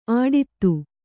♪ āḍittu